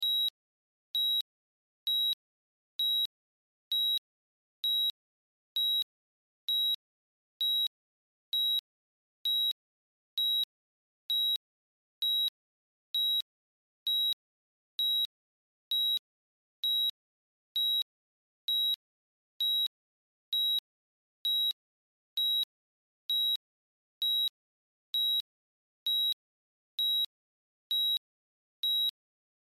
На этой странице собраны звуки кардиомониторов — от ровного ритма здорового сердца до тревожных сигналов критических состояний.
Звук сердцебиения на кардиомониторе